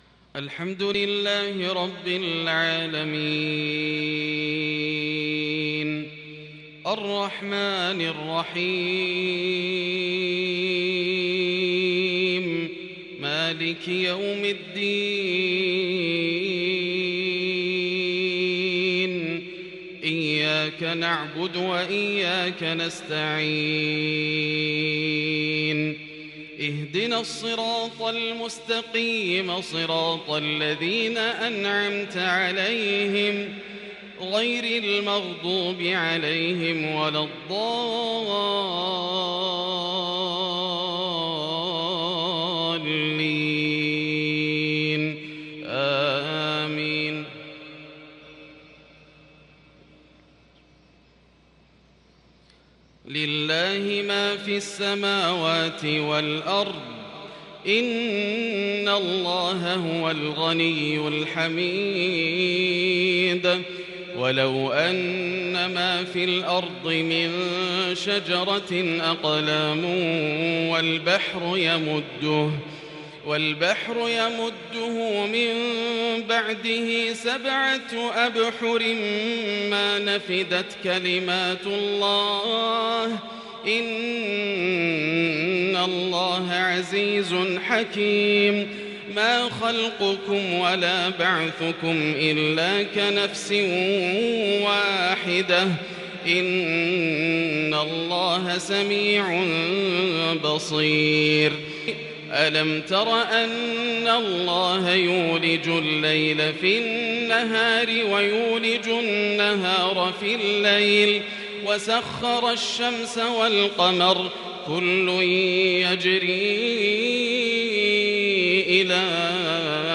صلاة الفجر من سورتي لقمان والحشر| يوم الخميس ١-١-١٤٤٢ هـ | Fajr prayer from Surat Luqman and Al-Hashr 20/8/2020 > 1442 🕋 > الفروض - تلاوات الحرمين